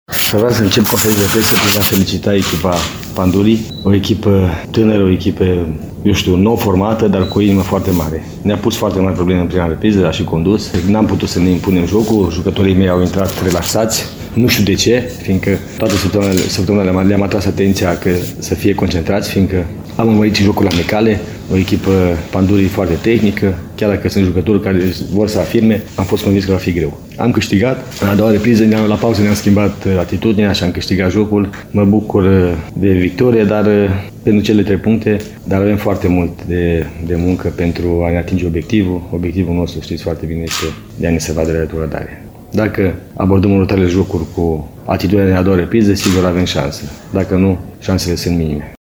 Antrenorul Reșiței, Dorinel Munteanu, aflat la prima victorie cu CSM, este mulțumit doar de repriza a doua: